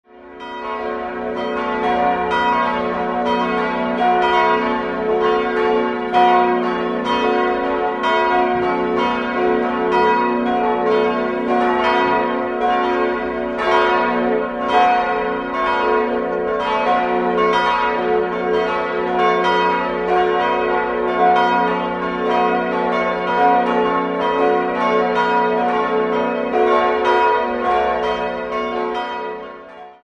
4-stimmiges ausgefülltes Fis-Moll-Geläute: fis'-a'-h'-cis''